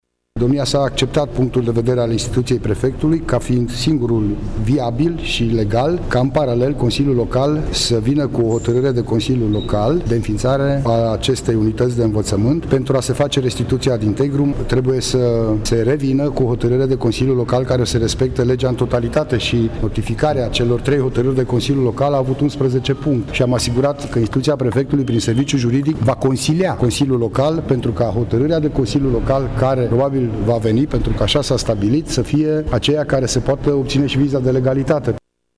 Lucian Goga a precizat că Nunțiul papal a acceptat punctul de vedere al Prefecturii: